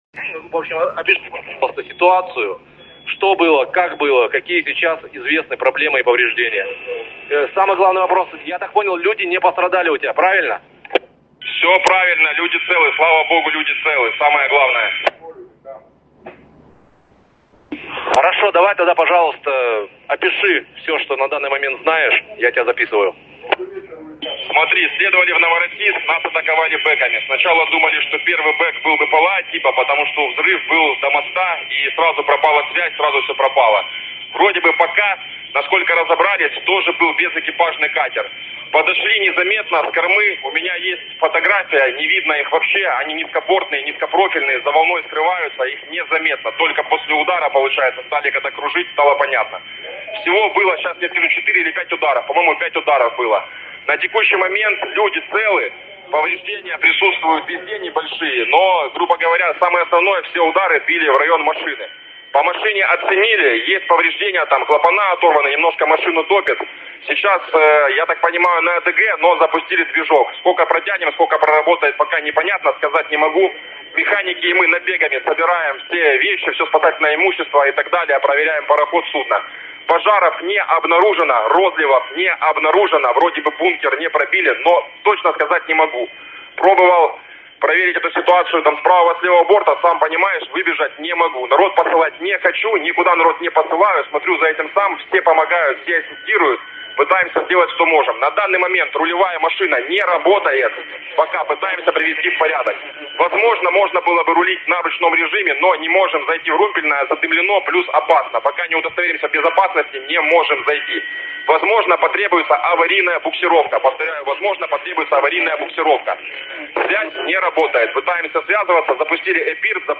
Радіообмін з екіпажем підбитого танкера (під прапором Гамбії) – зверніть увагу, розмова йде чистою російською мовою.